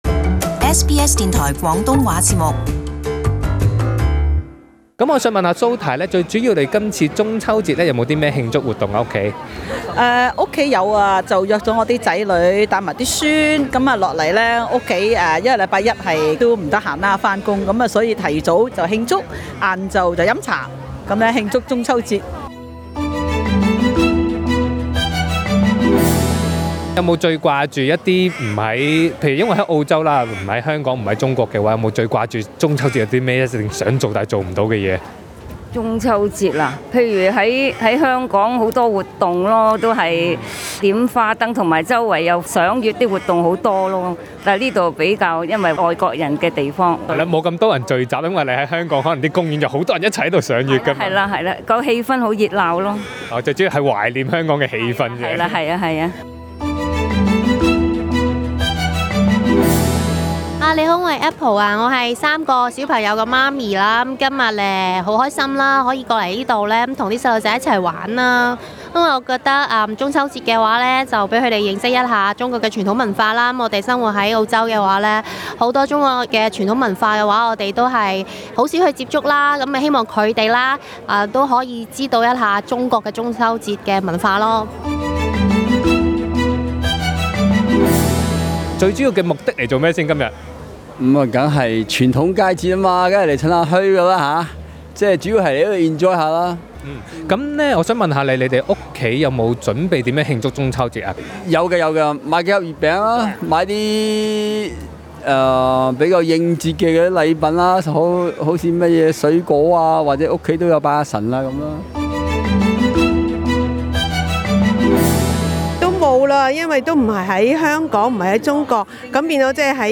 SBS 广东话节目组于过去的周末出席了两个位于墨尔本及雪梨的中秋节庆活动，与听众同庆佳节。